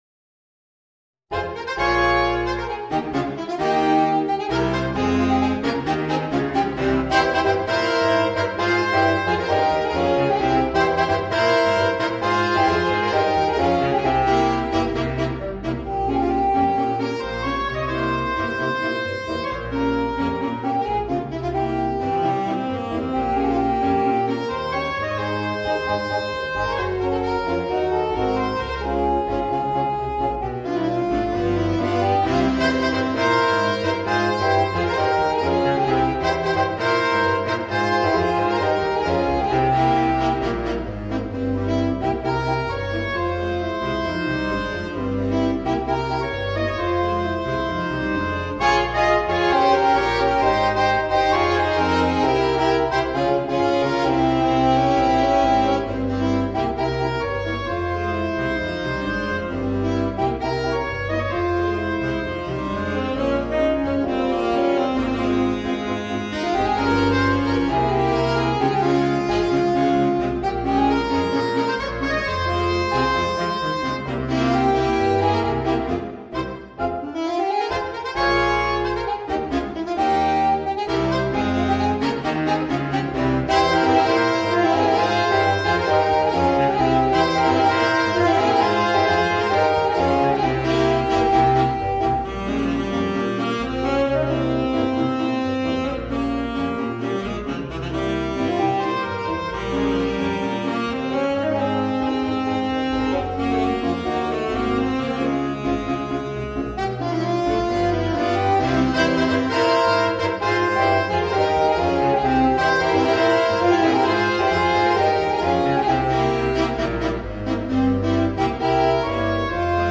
アルトサックス三重奏+ピアノ